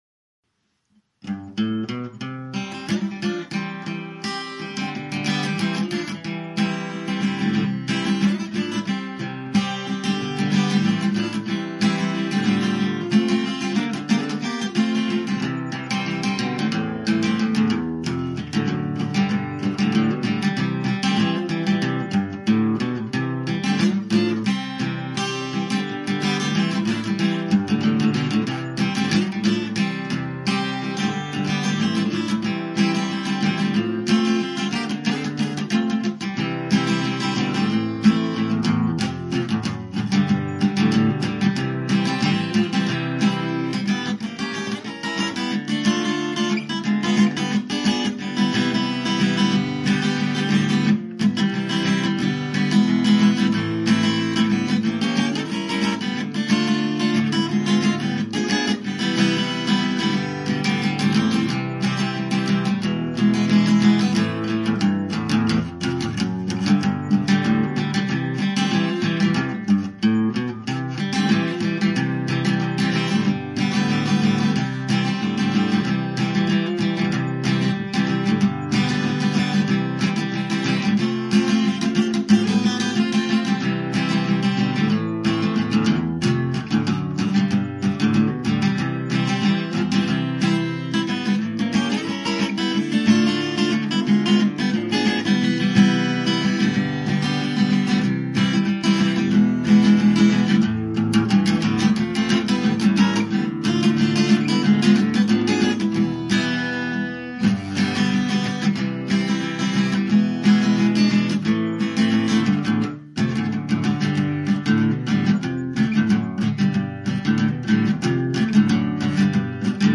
Tag: 搞怪 即兴的 跳汰机 吉他